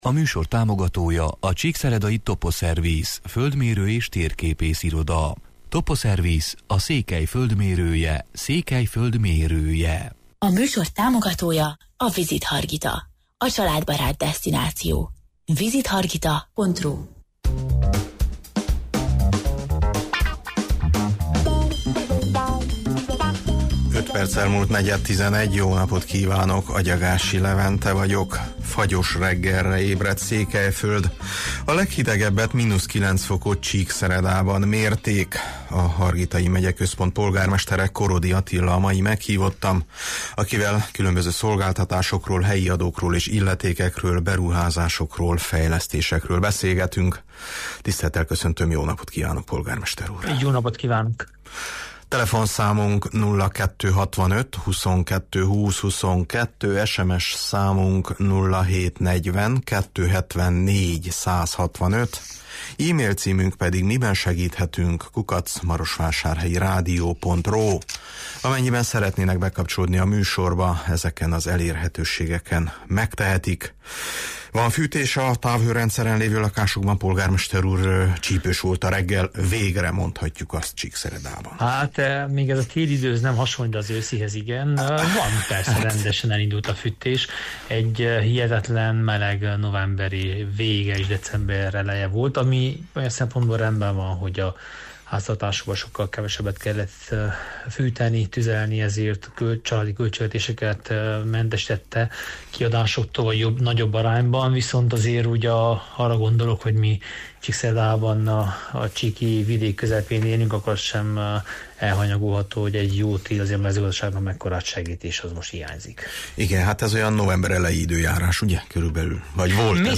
A hargitai megyeközpont polgármestere, Korodi Attila a mai meghívottam, akivel különböző szolgáltatásokról, helyi adókról és illetékekről, beruházásokról, fejlesztésekről beszélgetünk: